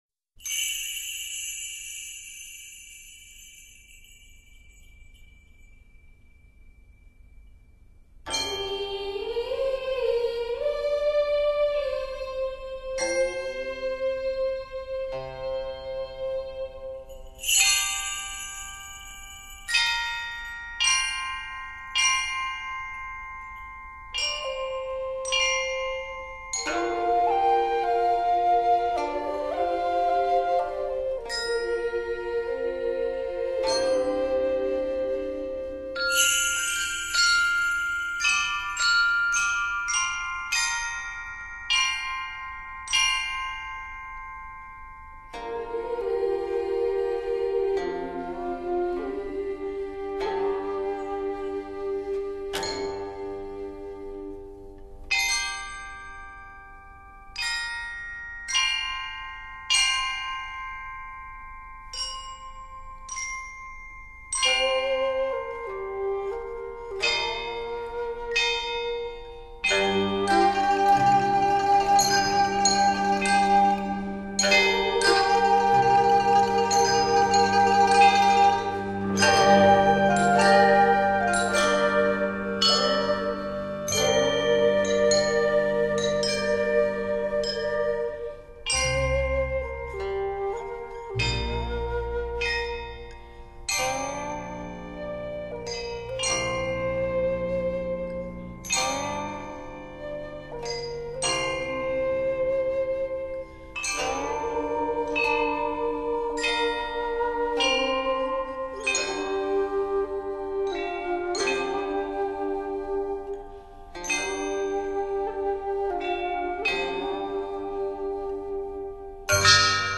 弥漫了Hi-Fi CD所标榜的硬件音效与动态指标。
七首纯音乐以敲击乐器为主
以编钟、编磬、配合二胡、琵琶及古琴、交织演奏、表现出乐曲依依惜别之情，乐器的音色和质感鲜明。